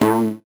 Index of /musicradar/8-bit-bonanza-samples/VocoBit Hits